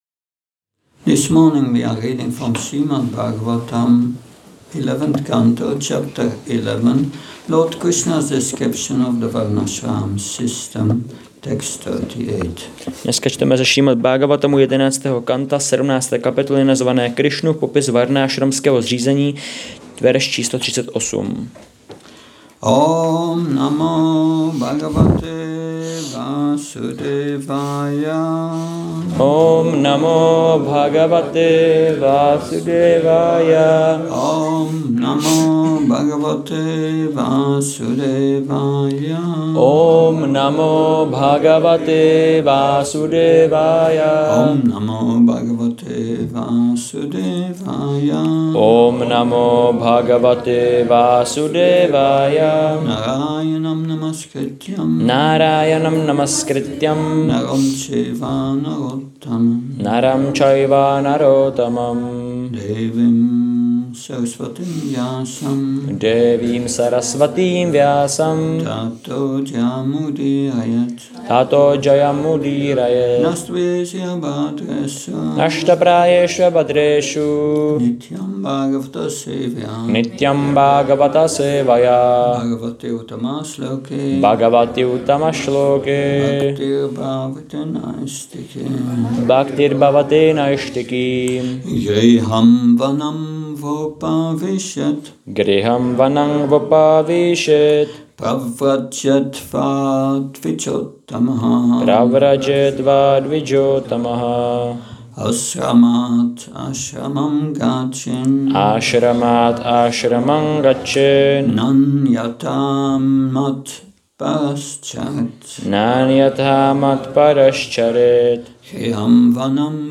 Přednáška SB-11.17.38 – Šrí Šrí Nitái Navadvípačandra mandir